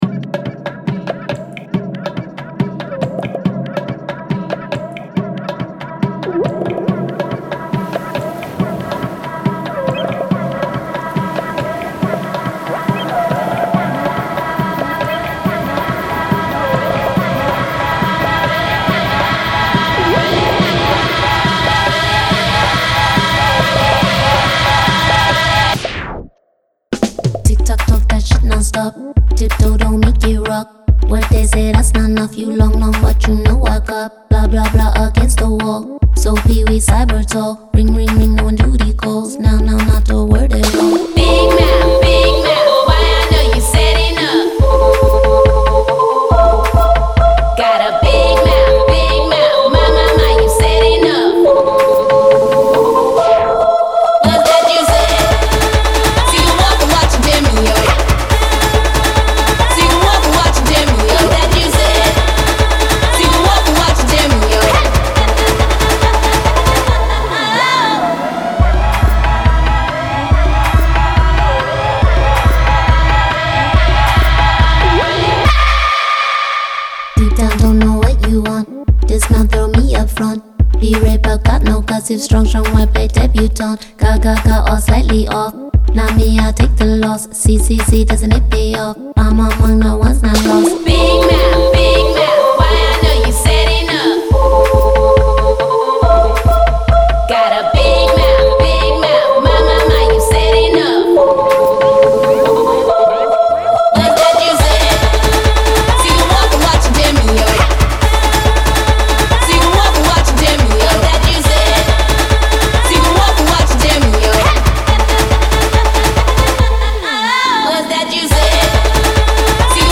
It’s pure fun!